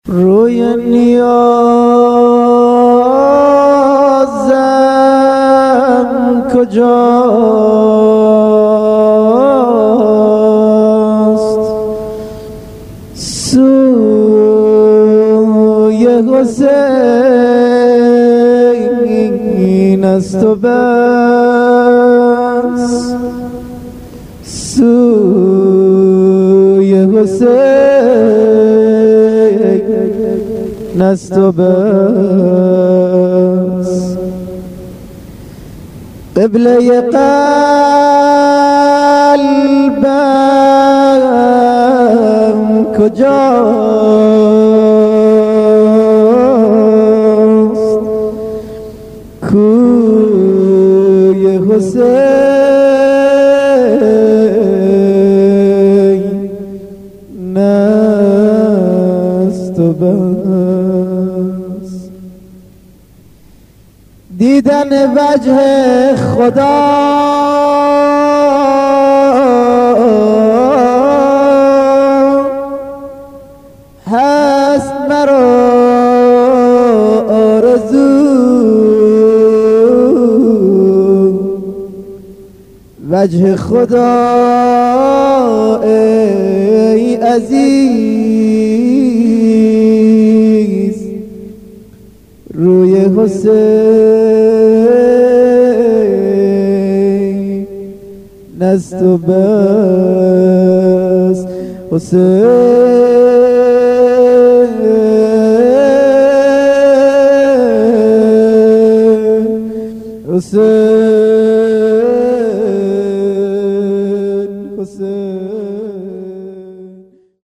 نوا - روی نیازم کجاست...